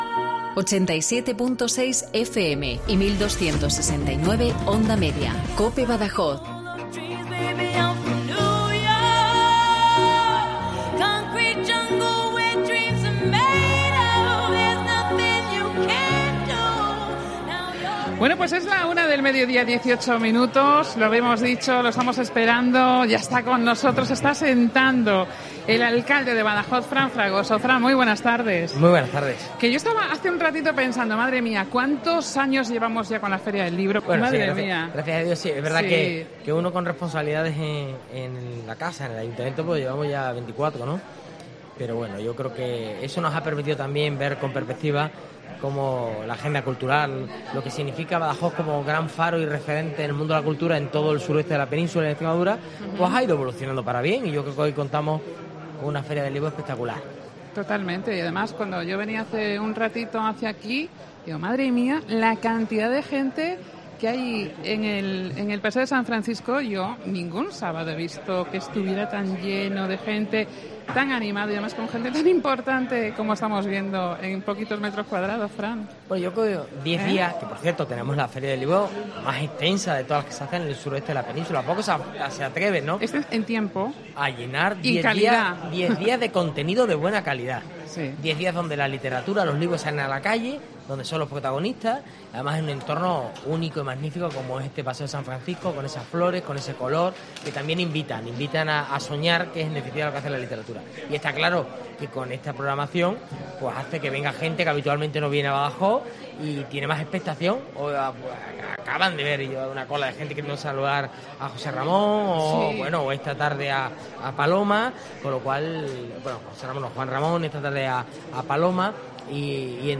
Entrevista al alcalde de Badajoz Fran Fragoso en la 38º Feria del Libro
AUDIO: Hoy hemos hecho programación especial con motivo de la 38 edición de la Feria del Libro de Badajoz en el paseo de San Francisco de Badajoz,...